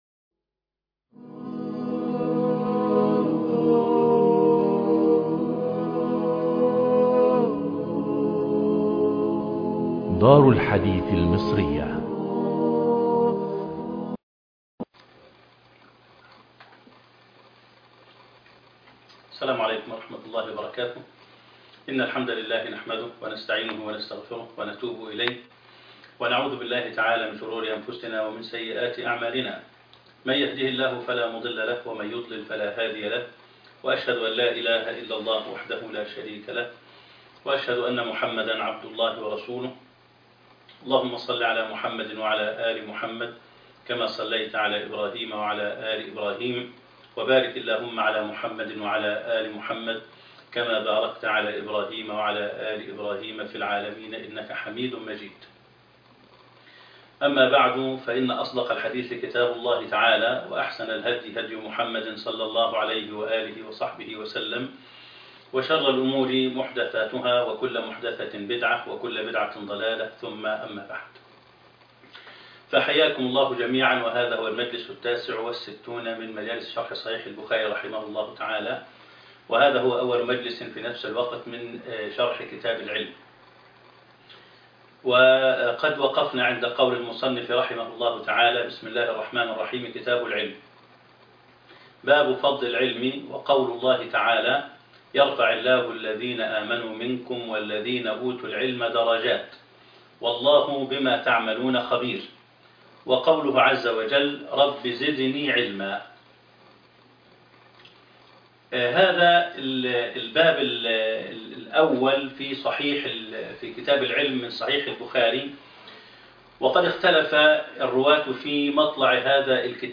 الدرس ( 69)كتاب العلم باب فضل العلم - مجالس شرح صحيح الإمام البخاري